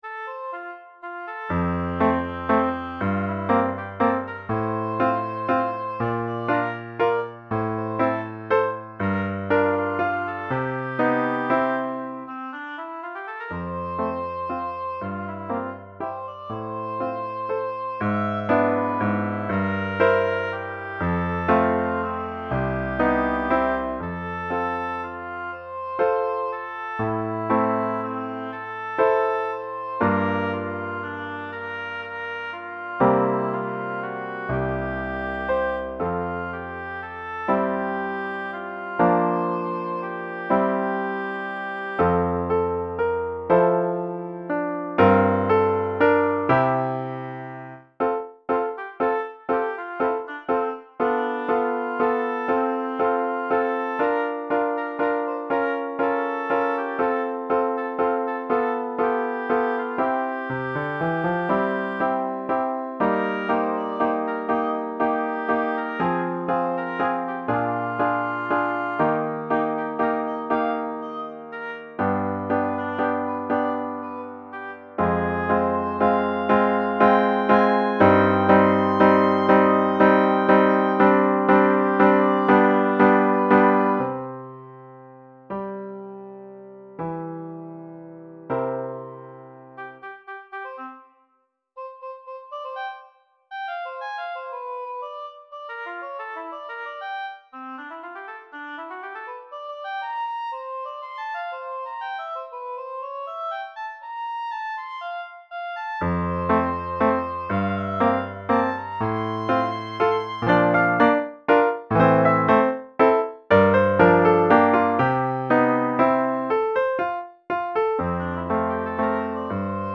Pour hautbois et piano DEGRE FIN DE CYCLE 1